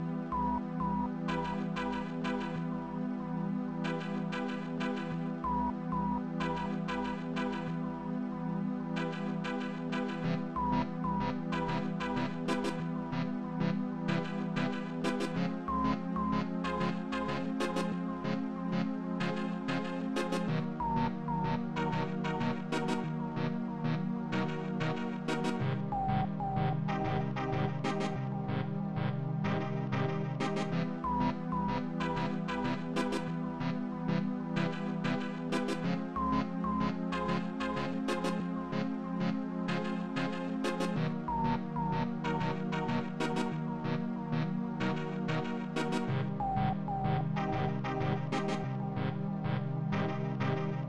Digital Sound Interface Kit RIFF Module
2 channels